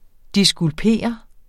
Udtale [ disgulˈpeˀʌ ]